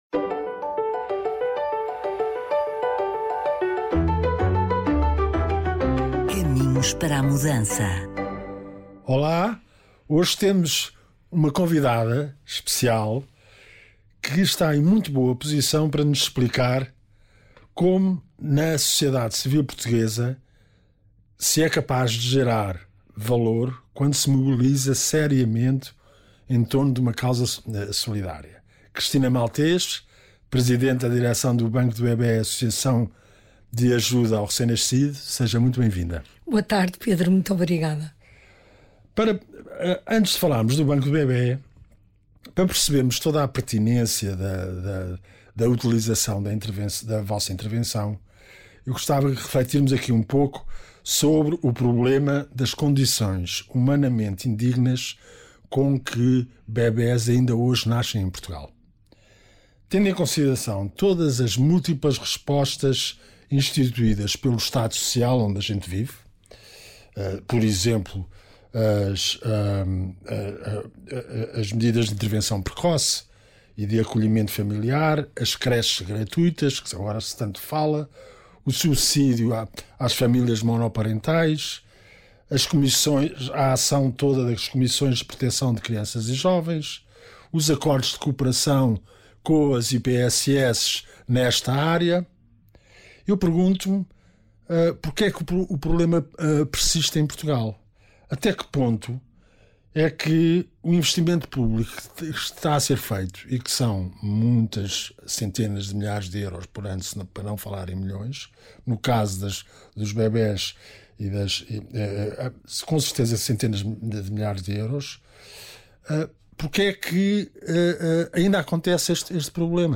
Oiça esta conversa e perceba até que ponto uma equipa composta exclusivamente por colaboradoras voluntárias é capaz de transformar a vida de muitas mães de recém-nascidos em situação de grande vulnerabilidade.